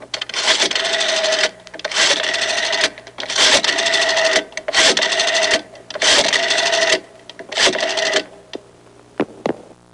Dialing A Rotary Phone Sound Effect
Download a high-quality dialing a rotary phone sound effect.
dialing-a-rotary-phone.mp3